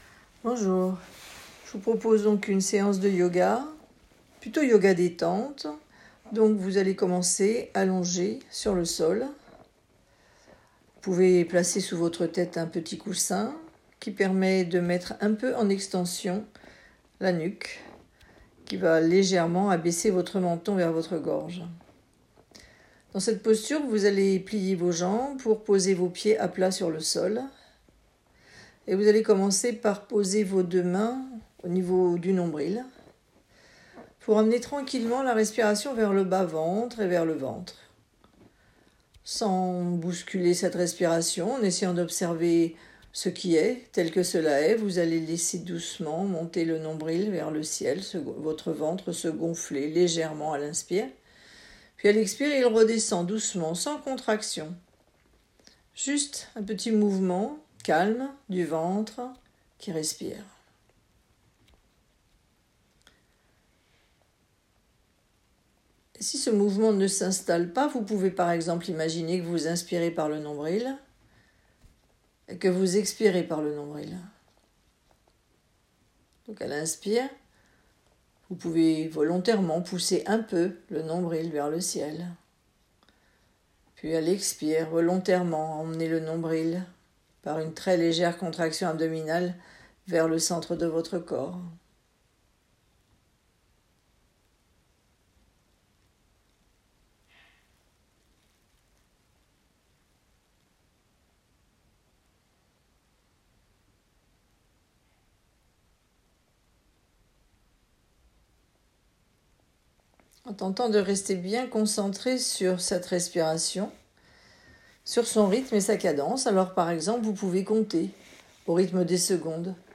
Seance-de-Yoga.m4a